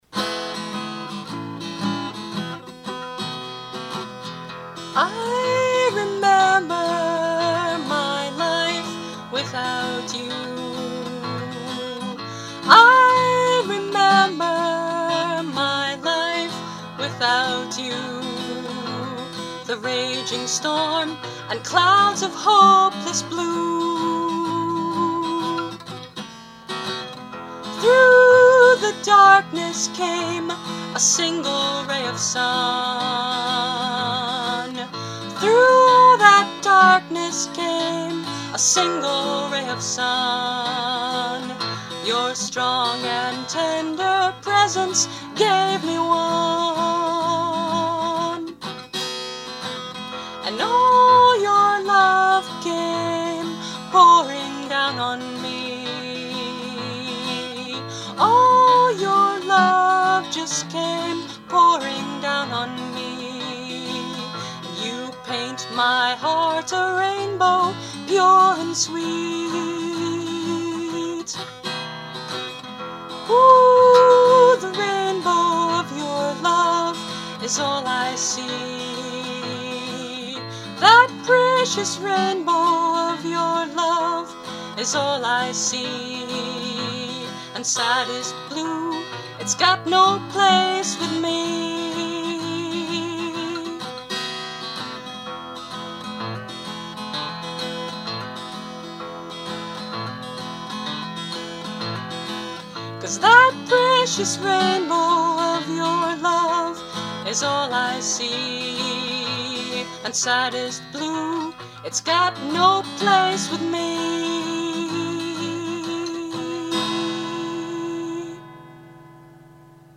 On guitar, with my right pinkie and ring finger feeling much better.